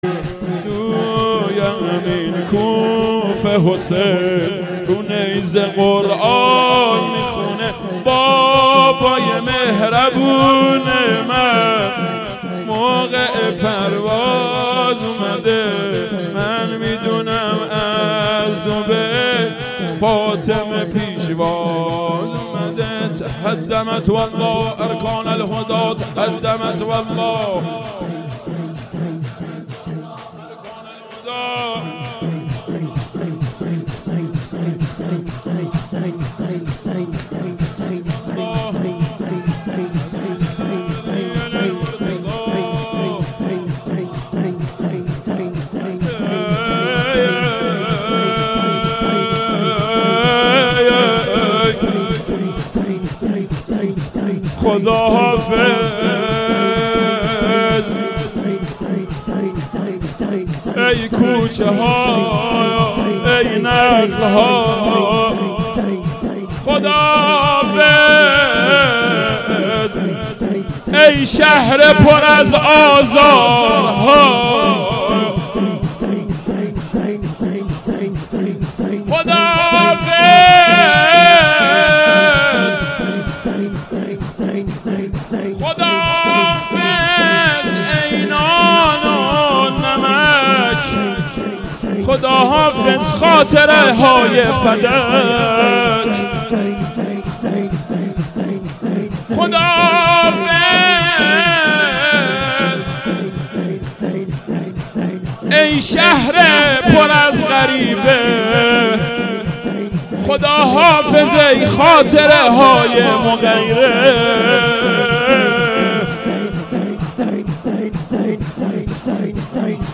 شوراول شهادت امیرالمومنین ع هیئت محبین العباس و فاطمیون و هیئت الرضا ملاثانی.mp3